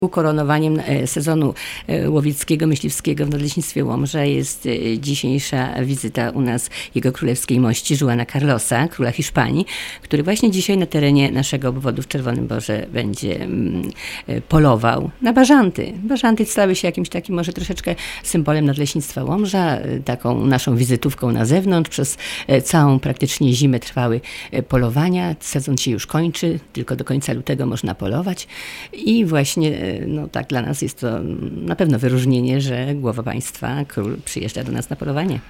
Relacja Radia BAB